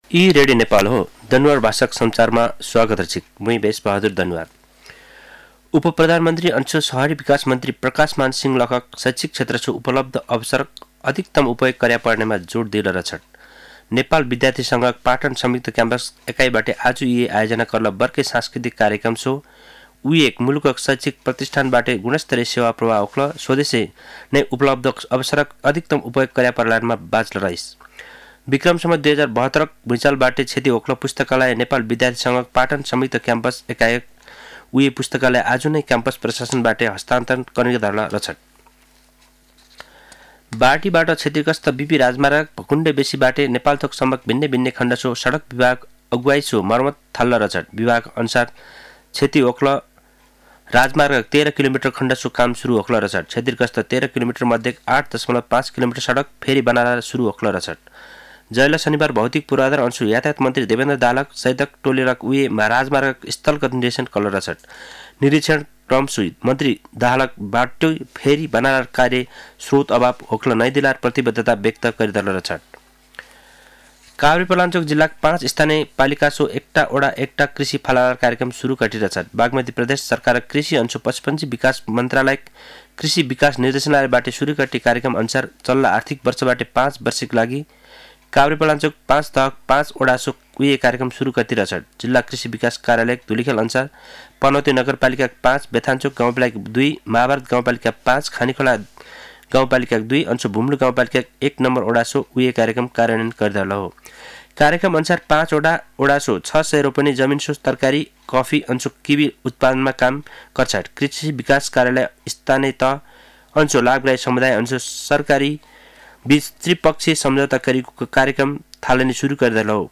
दनुवार भाषामा समाचार : १ फागुन , २०८१
Danuwar-news-3.mp3